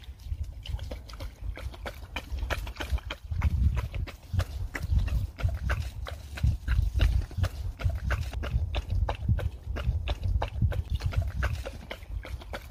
Tiếng Chó Liếm nước, Uống nước, Ăn thức ăn…
Thể loại: Tiếng động
Description: Tiếng chó liếm, húp, nhấp, táp nước vang lên nhịp nhàng, xen lẫn âm tõm… chụp… lách tách khi đầu lưỡi chạm mặt nước và kéo từng giọt vào miệng. Khi uống, tiếng nuốt khẽ “ực… ực” hòa cùng hơi thở gấp gáp, tạo cảm giác sống động như đang ở ngay bên cạnh. Trong hiệu ứng âm thanh (Dog Lapping up Water Sounds) cho video hay phim...
tieng-cho-liem-nuoc-uong-nuoc-an-thuc-an-www_tiengdong_com.mp3